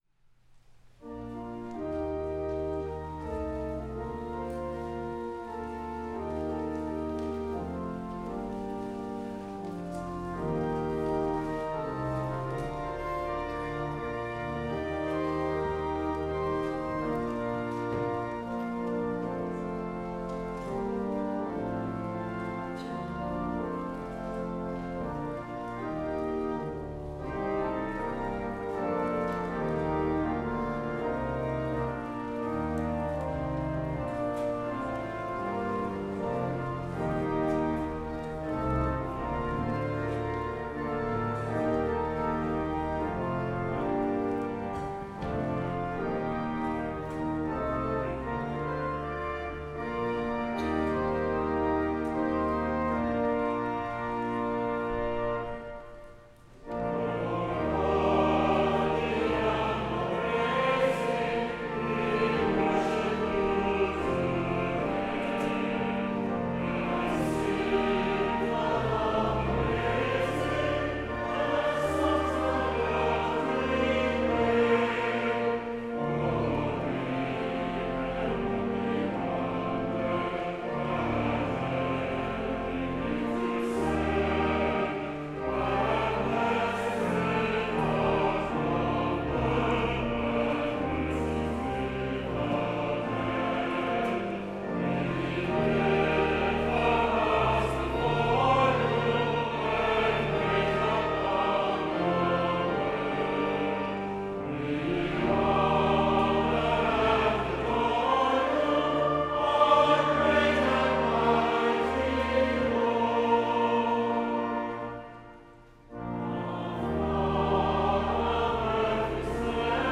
HYMN  O God Beyond All Praising               Music: Gustav Holst (1874-1934)  Descant: Richard Proulx (1937-2010)
Fairlawn Avenue Senior Choir and congregation